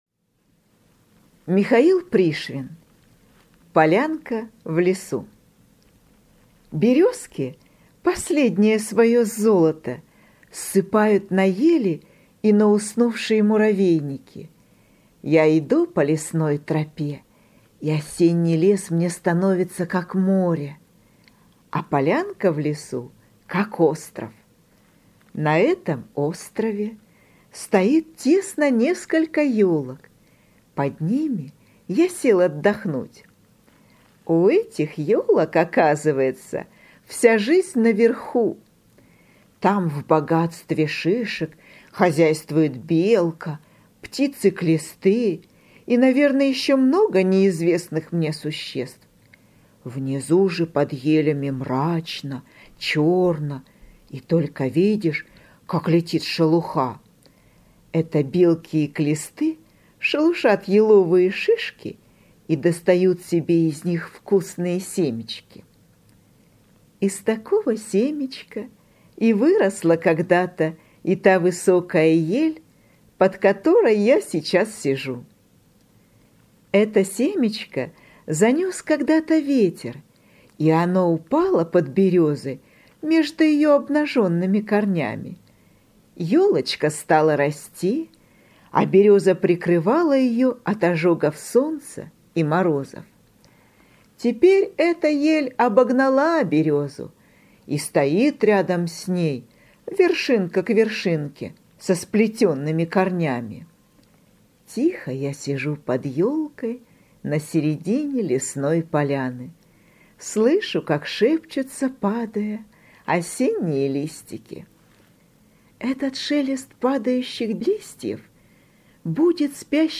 Полянка в лесу – Пришвин М.М. (аудиоверсия)